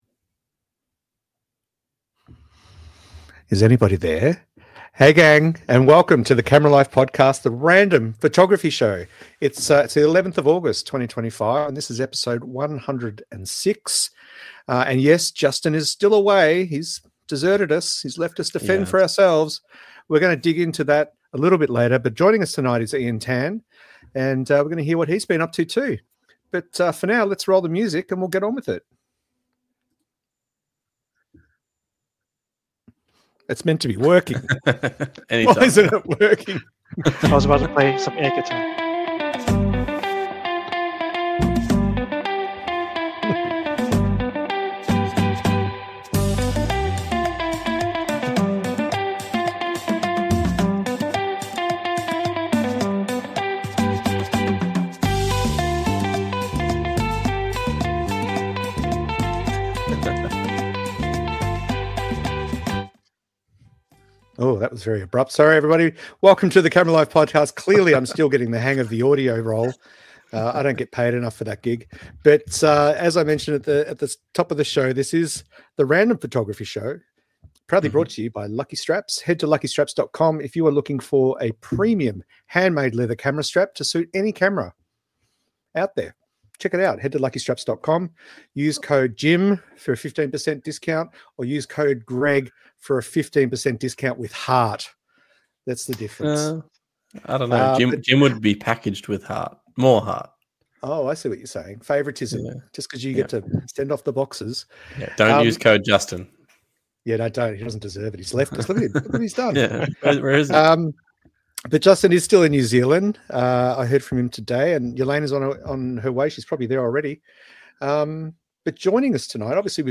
===========================================THE CAMERA LIFE - LIVE PHOTOGRAPHY PODCAST==========